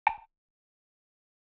Perc1.wav